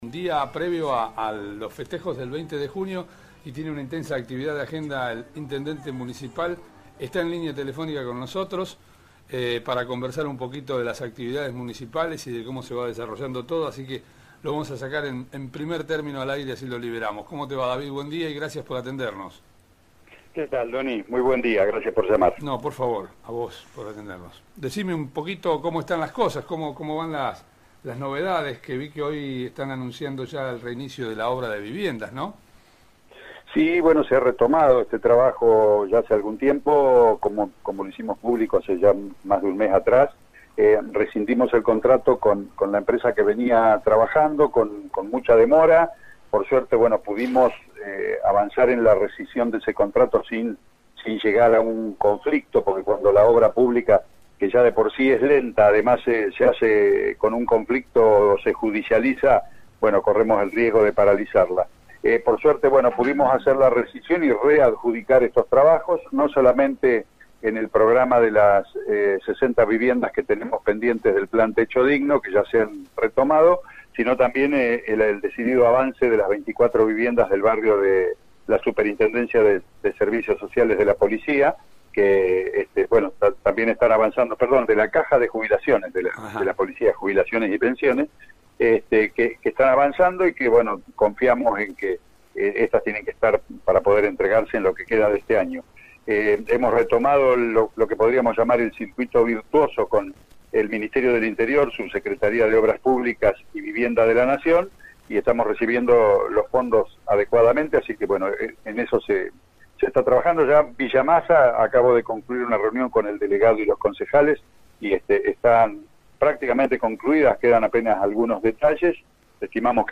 Entrevista exclusiva al Intendente Municipal de Adolfo Alsina David Hirtz